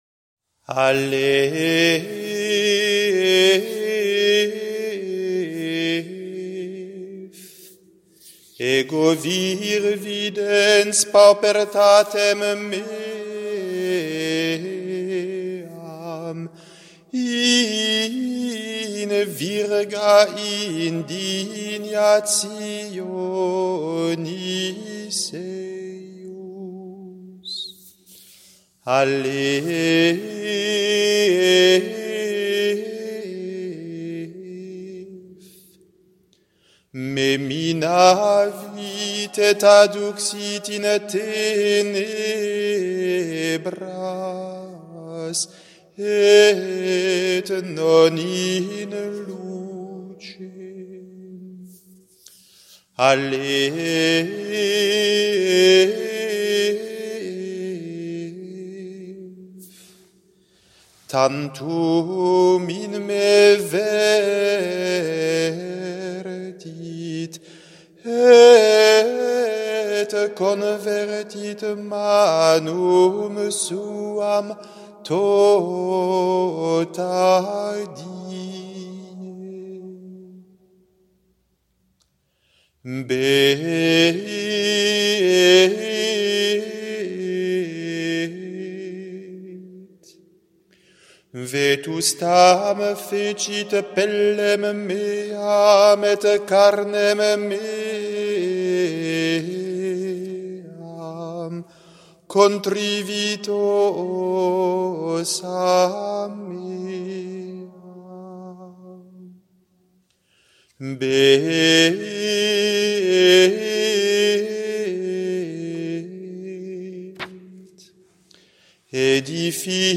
Le chant d’une lamentation (6/9)